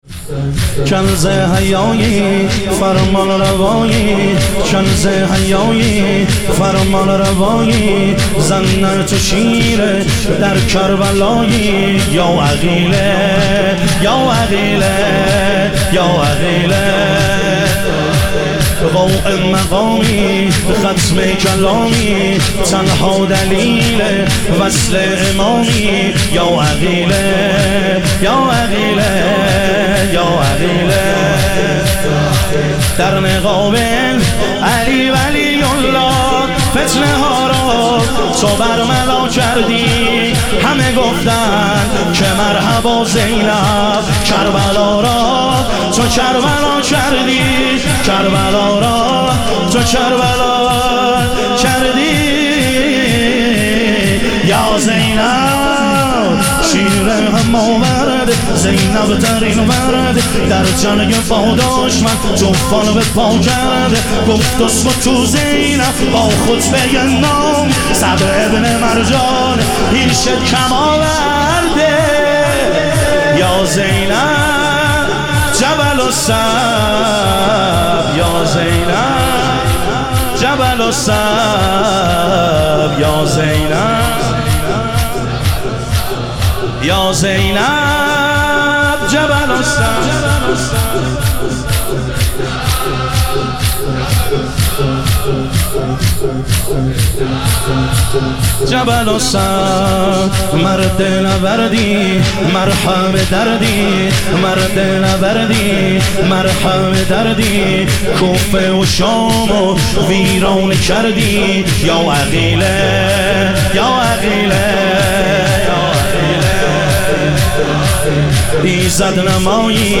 شهادت حضرت زینب کبری علیها سلام - شور